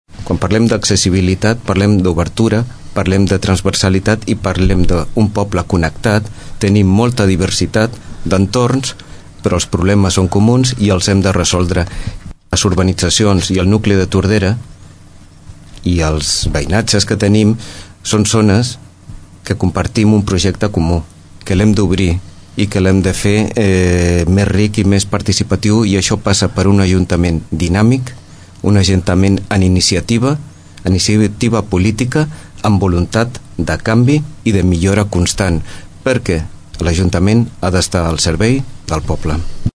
debat psc
debat-psc.mp3